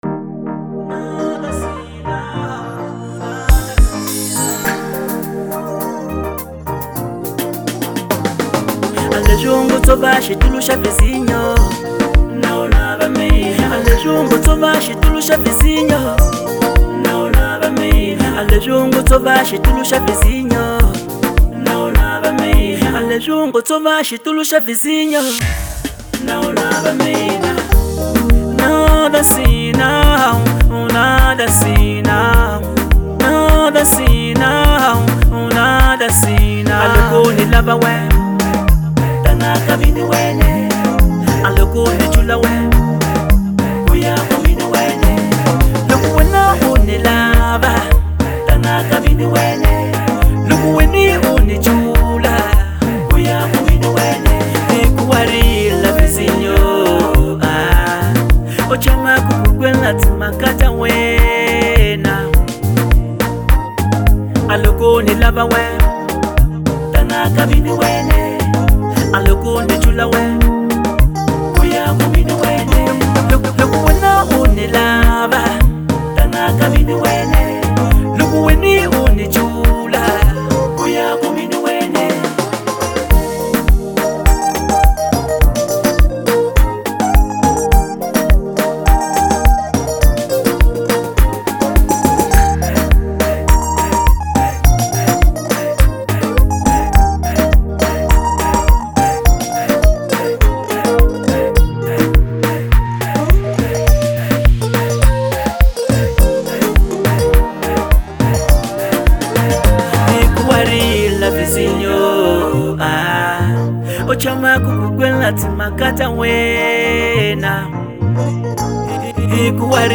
| Afro Classic